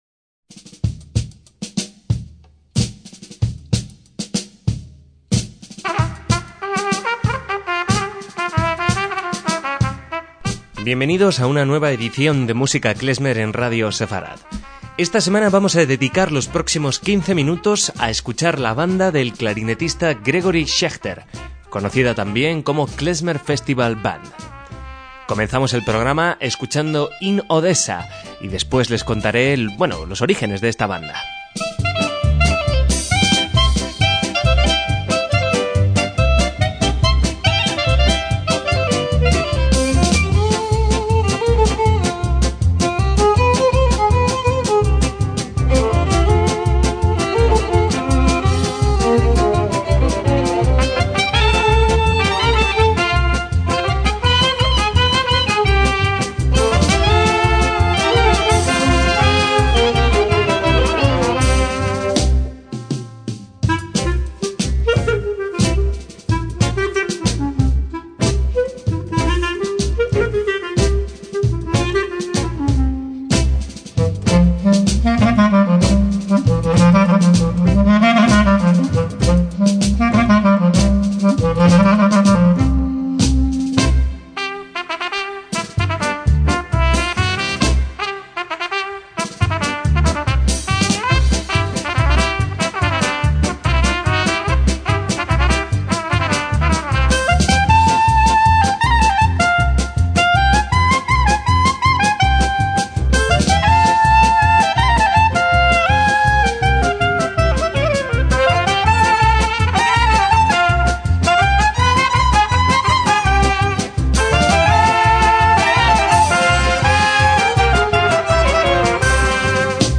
MÚSICA KLEZMER
clarinete